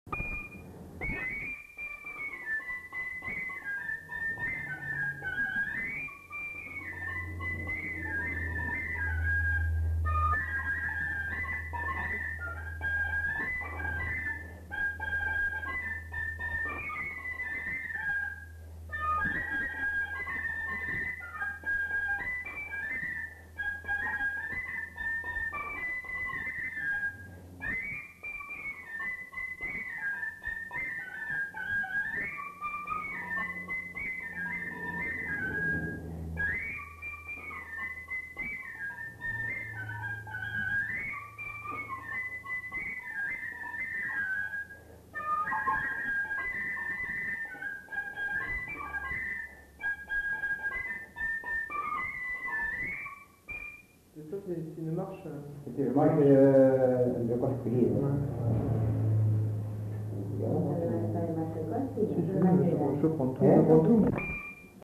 Aire culturelle : Bazadais
Lieu : Bazas
Genre : morceau instrumental
Instrument de musique : fifre